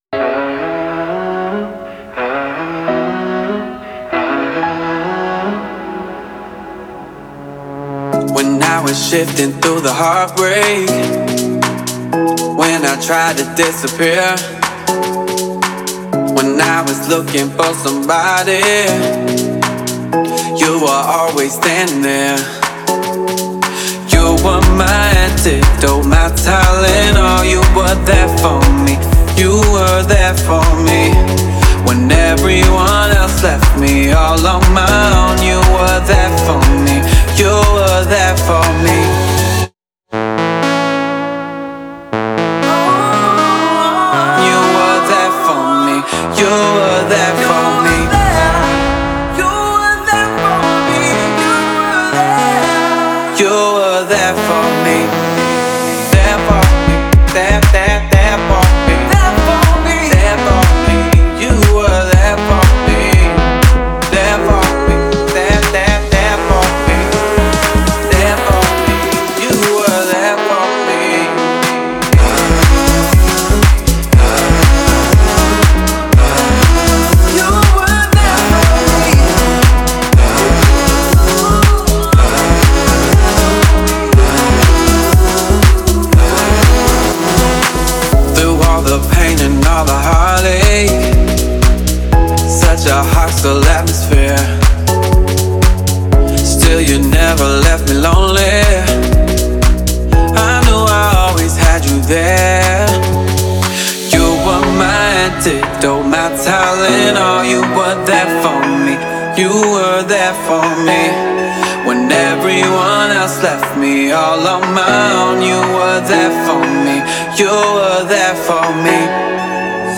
это мелодичная песня в жанре поп с элементами R&B